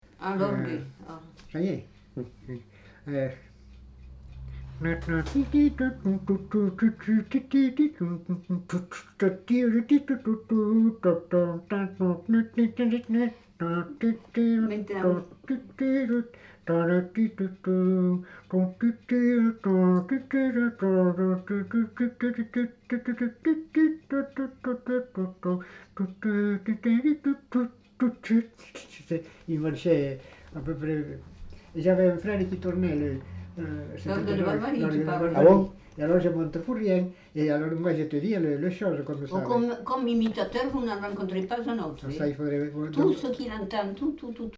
Imitation de l'orgue de barbarie
Aire culturelle : Lauragais
Lieu : Saint-Félix-Lauragais
Genre : expression vocale
Type de voix : voix d'homme
Production du son : effet de voix
Classification : imitation d'un instrument de musique